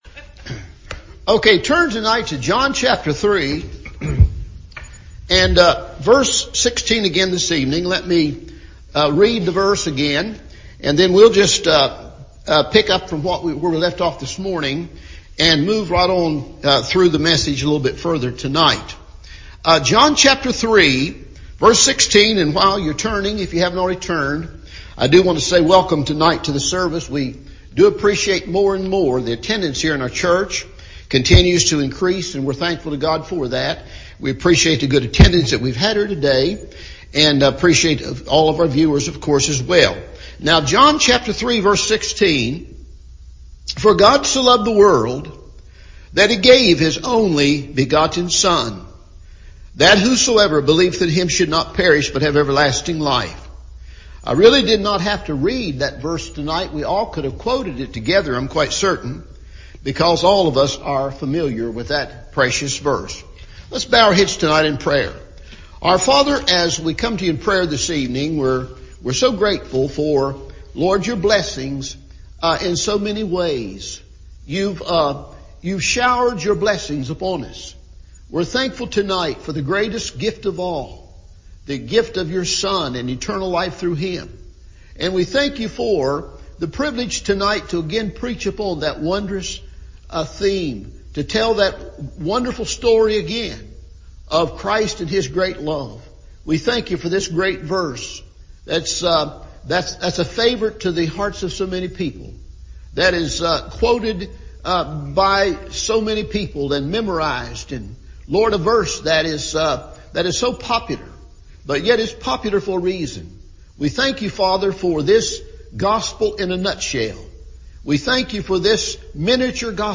John 3:16 Part 2 – Evening Service
Sermon